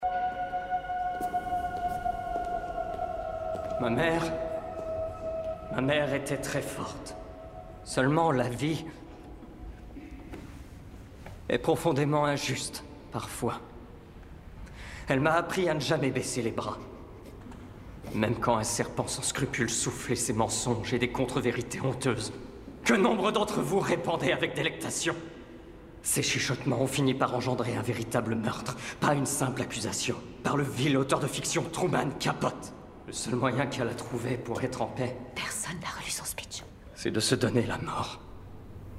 Doublage rôle de Jimmy Woodward in Feud saison 2
18 - 43 ans - Baryton